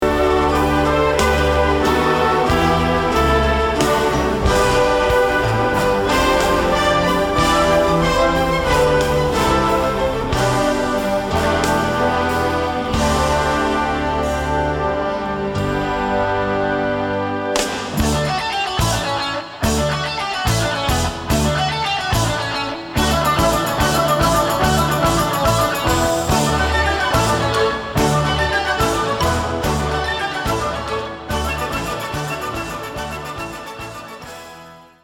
Startseite » sheet music Full Score music